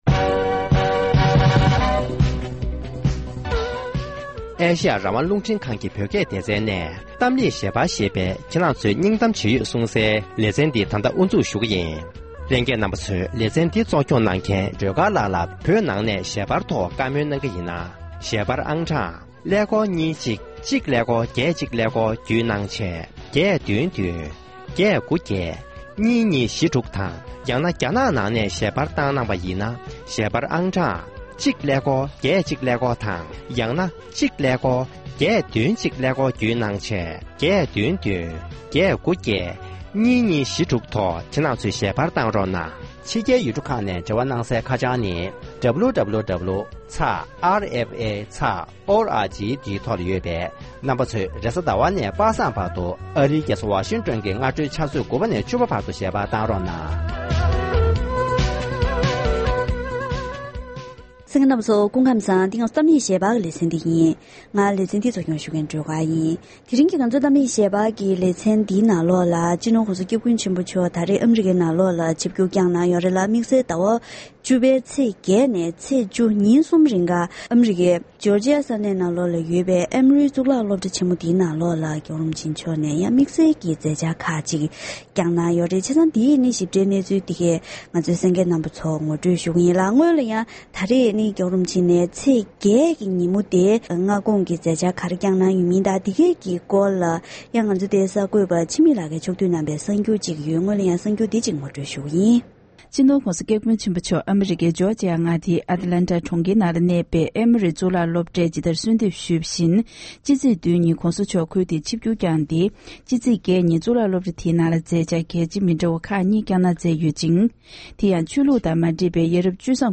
ཨ་རིའི་ཨེ་མོ་རི་གཙུག་ལག་སློབ་གྲྭར་བཀའ་སློབ་གནང་བཞིན་པ།